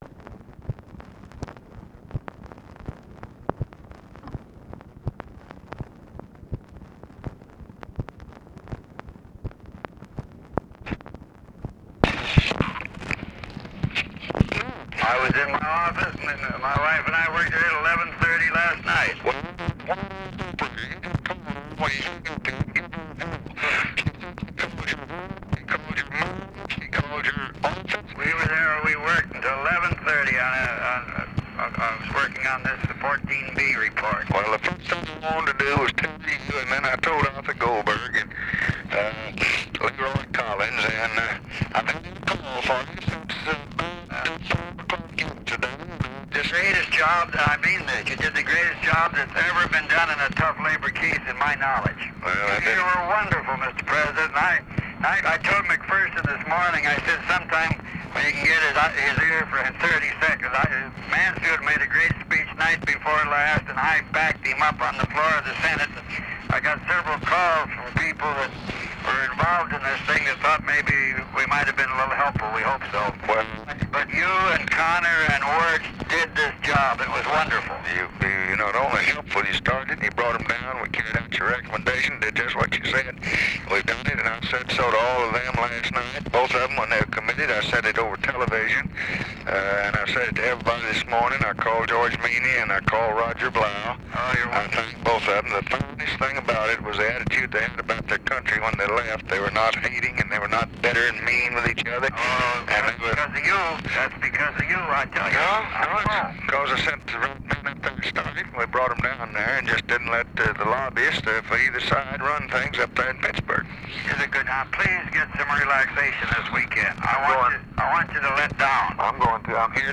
Conversation with WAYNE MORSE, September 4, 1965
Secret White House Tapes